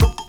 Wu-RZA-Kick 34.WAV